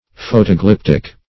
Photoglyptic \Pho`to*glyp"tic\, a.